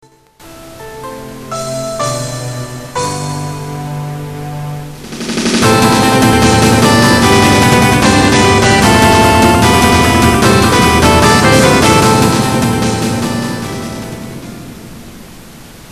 ツクールXP音源、デスクトップ録音なので、音が雑＆ノイズが入ってますが・・・。
でも、今までに無い曲風になってますので、期待しないほうがいいかもしれません…。＞＜